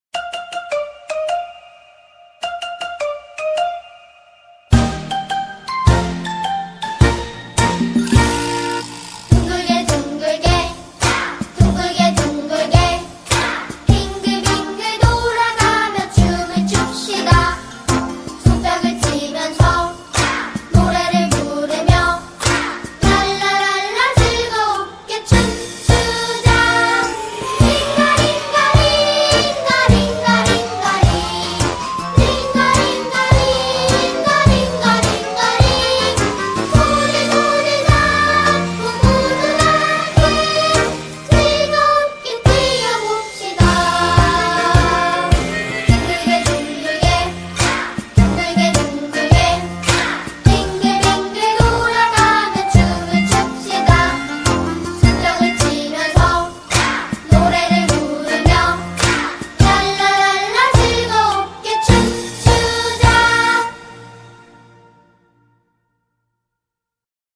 2025-01-23 21:04:49 Gênero: MPB Views